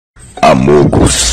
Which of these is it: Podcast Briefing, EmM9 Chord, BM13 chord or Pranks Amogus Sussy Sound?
Pranks Amogus Sussy Sound